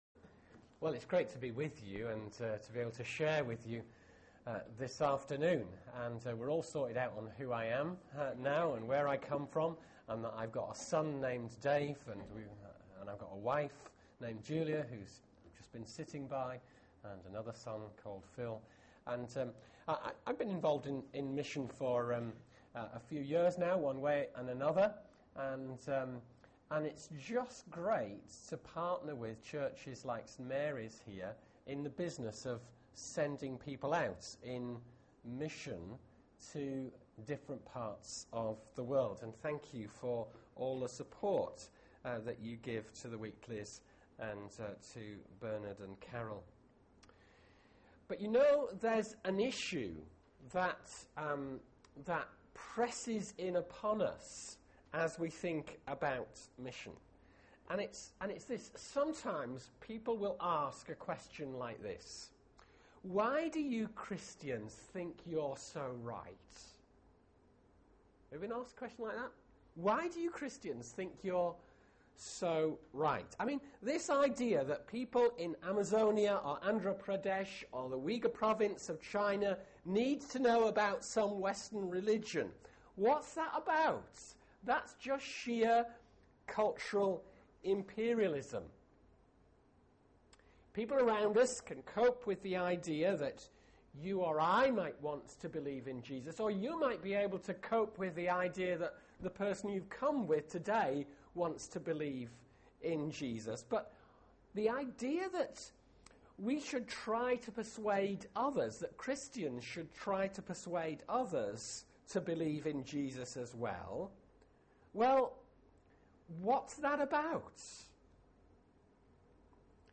Media for 4pm Service on Sun 05th Feb 2012 16:00 Speaker
World Focus Sunday Theme: What's so unique about Jesus? Sermon Search the media library There are recordings here going back several years.